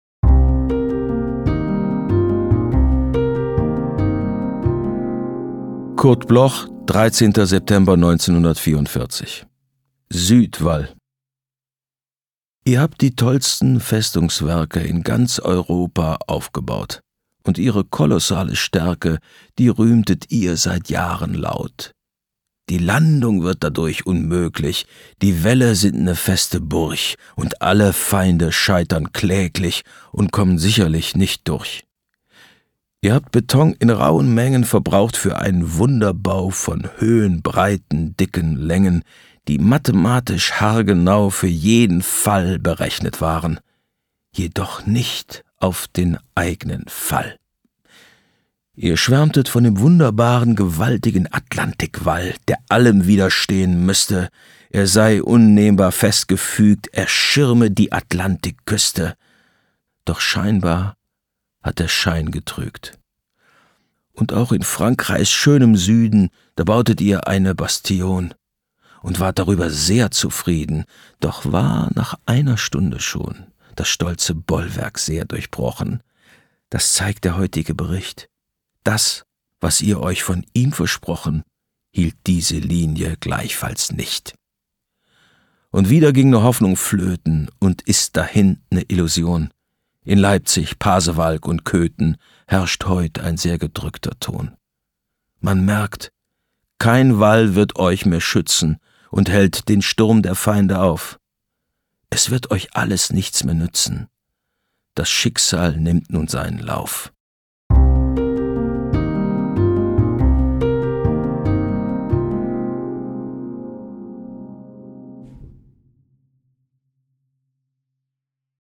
Bernhard Schütz (* 1959) ist ein deutscher Schauspieler und Sprecher.
Bernhard-Schuetz-Suedwall-mit-Musik_raw.mp3